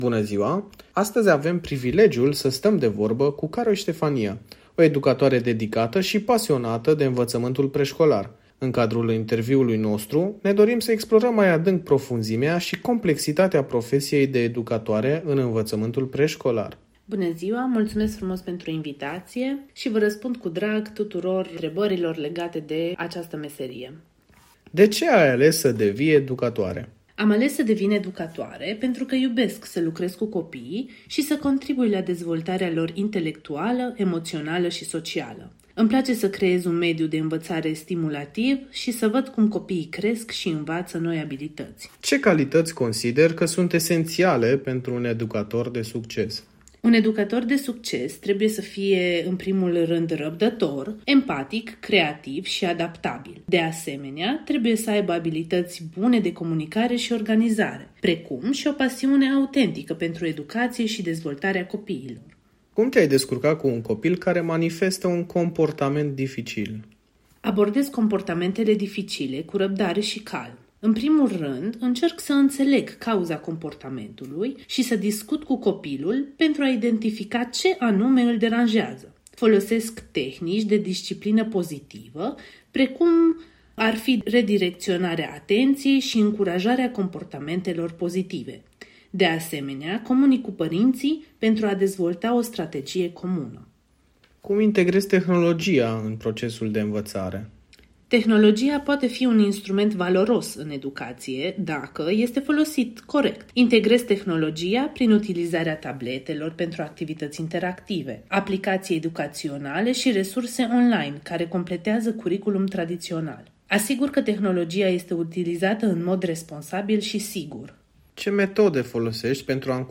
În cele ce urmează vă prezentăm un interviu relizat cu un cadru didactic dedicat meseriei.
Interviu-cadru-didactic-educator-1.mp3